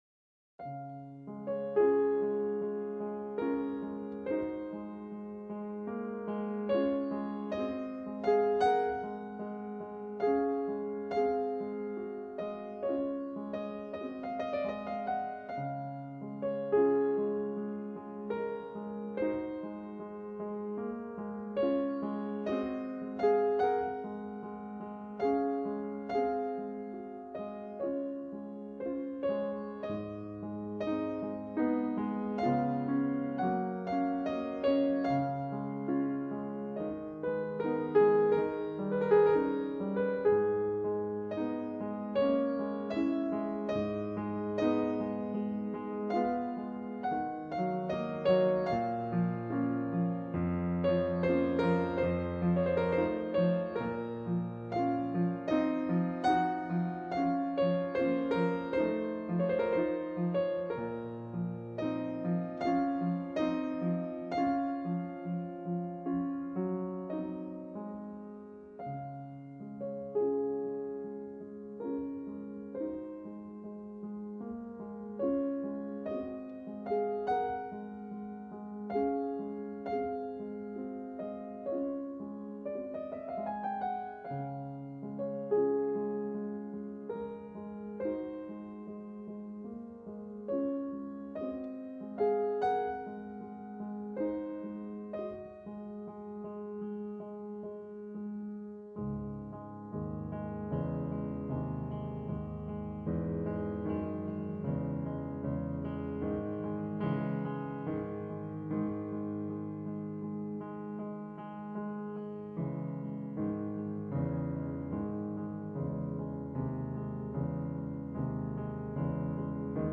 Infatti, il vero tema del pezzo non era la musica in primo piano, quella melodia immediata, tenera e suggestiva. Non era l’ascolto istintivo del pezzo che faceva emergere la sua verità: il suo significato vero era una cosa apparentemente monotona, tanto monotona da ridursi a una nota sola che si ripete continuamente, con qualche leggera variazione, dal principio alla fine.
E nello spazio restituito scandisce due, tre, quattro note.
Introduzione di don Luigi Giussani al cd della collana Spirto Gentil: Fryderyk Chopin, Brani scelti; Nikita Magaloff, Philips (1999, cd n. 10).